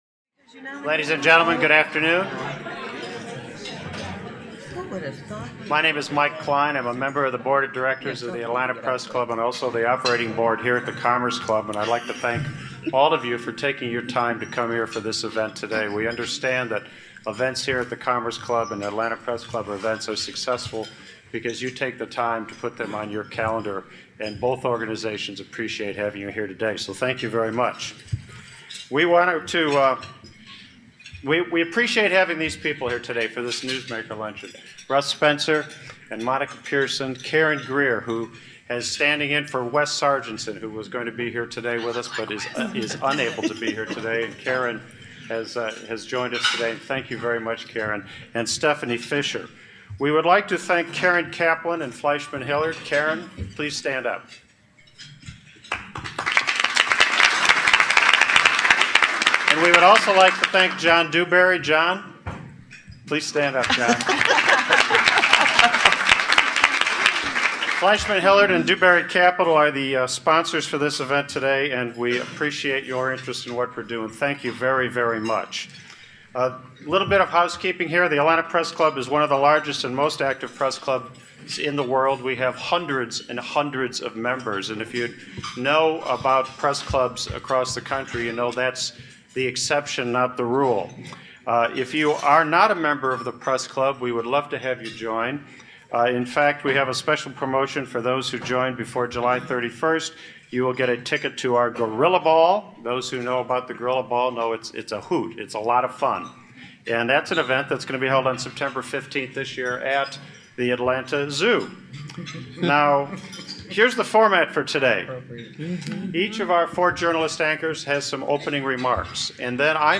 at a Newsmaker Luncheon on Thursday, June 28, at The Commerce Club.